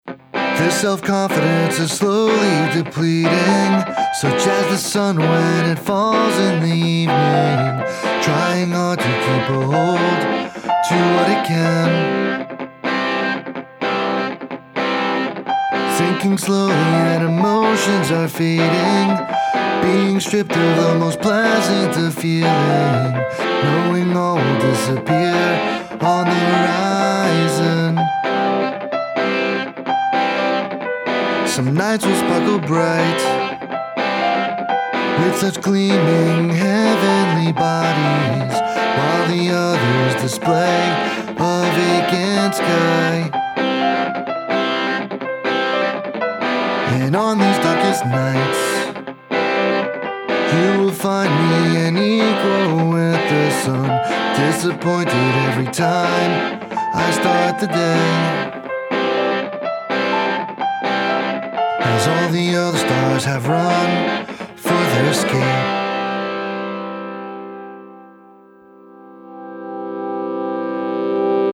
Vocals, Guitar, Drums
Keyboards, Vocals